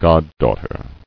[god·daugh·ter]